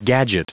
Prononciation du mot gadget en anglais (fichier audio)
Prononciation du mot : gadget